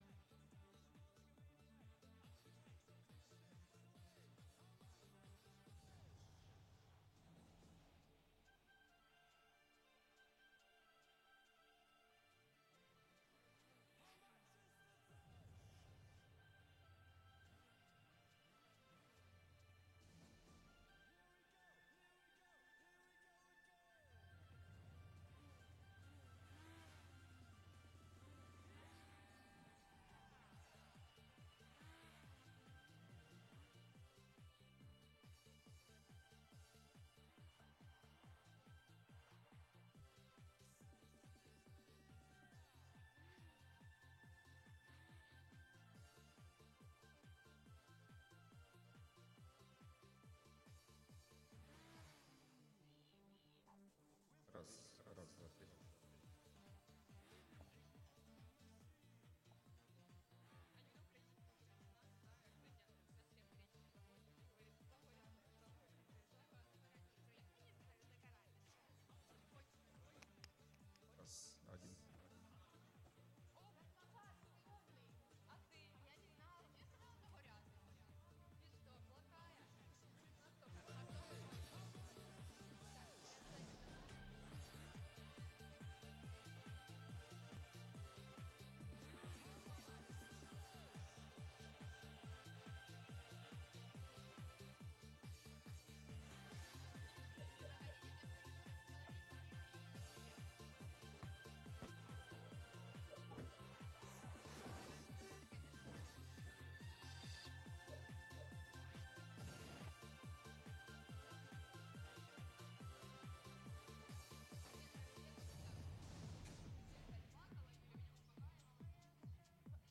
Караоке Одеса 😊🎤🎉 on 19-Oct-23-18:40:12
Постоянная ссылка URL (SEO) (SEO) Текущее время (SEO) Категория: Караоке вечори в Одесі Описание: Караоке Одеса караоке-бар "PRINCE"!